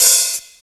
35 OP HAT 2.wav